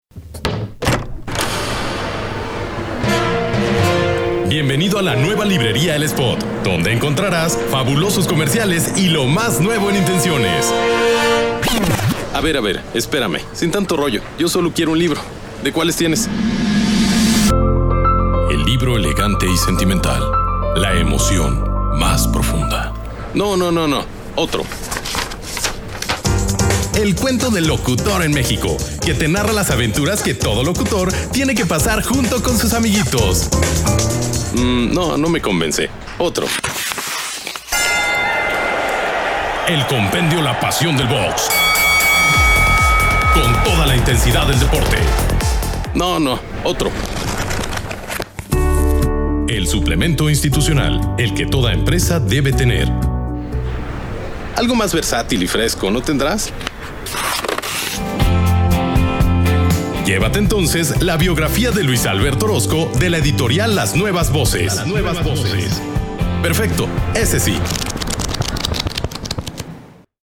Mexican broadcaster with 8 years of experience. Commercial, corporate, institutional locution, among others.
Sprechprobe: Sonstiges (Muttersprache):